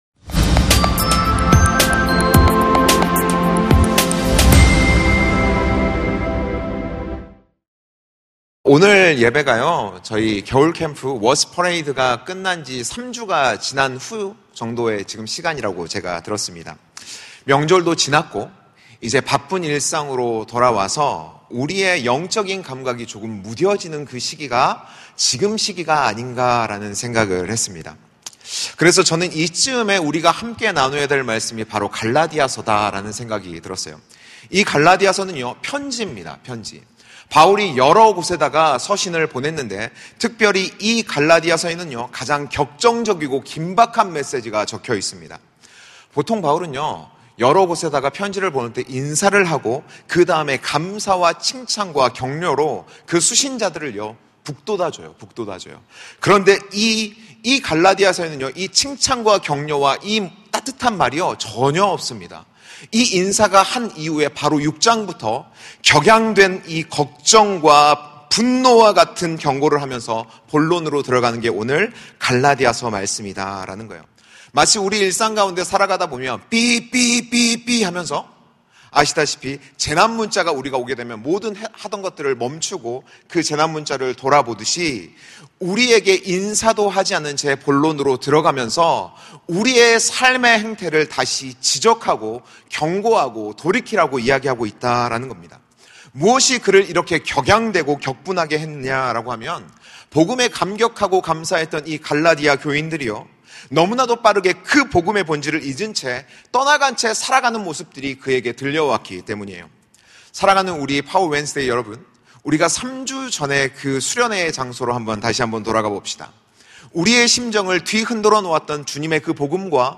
설교